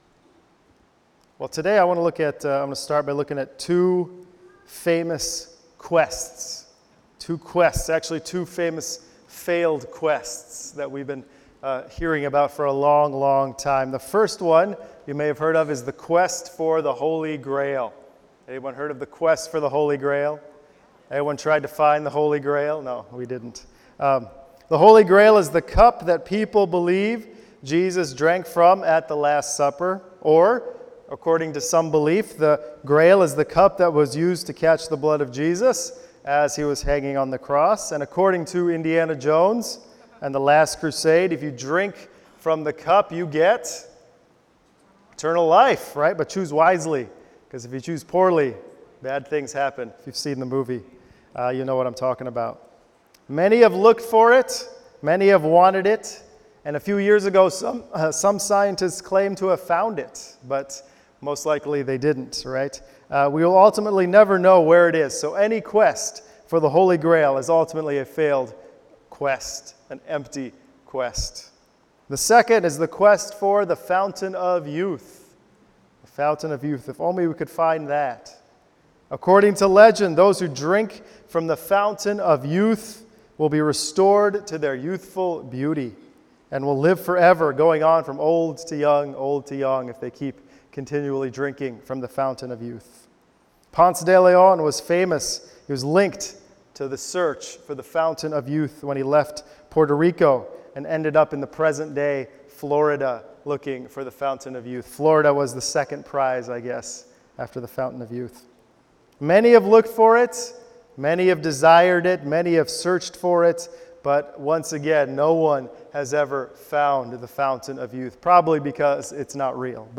Sermon 7.22.18.mp3